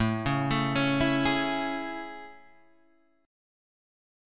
A Major 7th
AM7 chord {5 4 2 2 2 4} chord